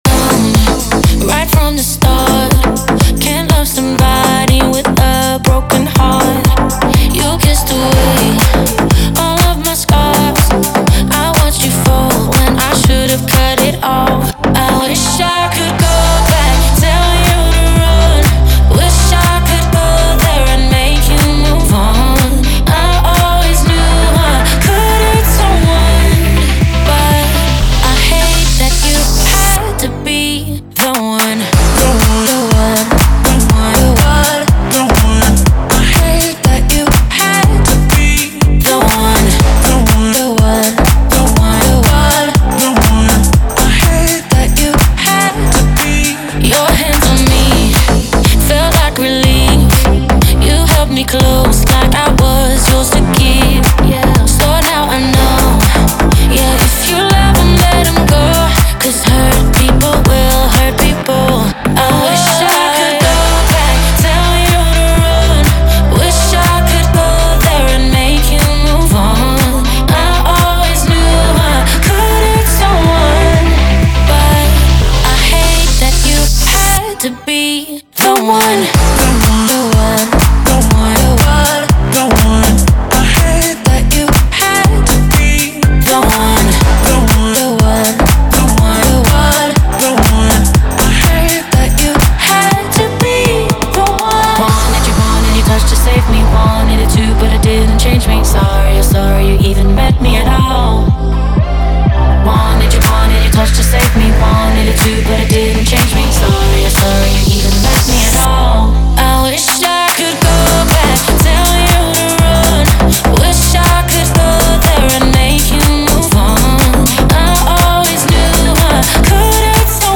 это яркая и энергичная песня в жанре поп с элементами EDM